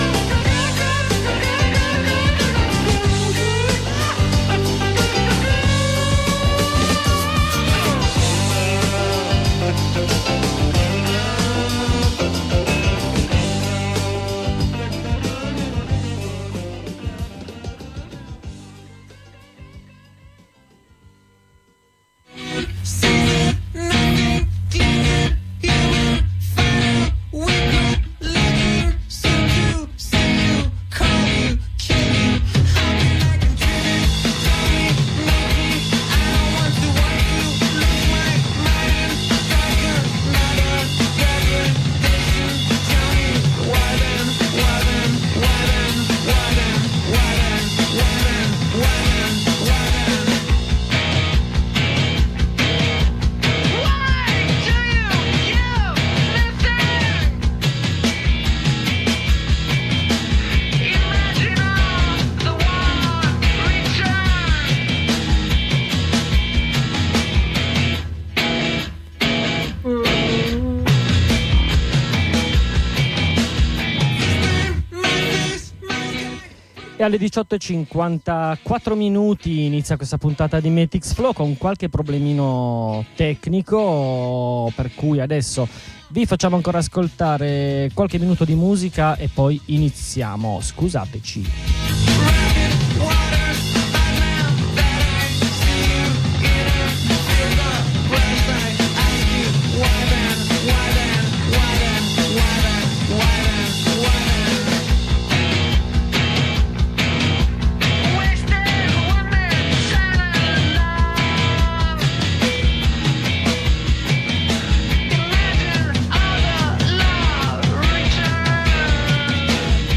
Continuano gli aggiornamenti sui e sulle migranti in particolare sulle lotte contro i CPR. Oggi collegamenti da Torino e da Caltanisetta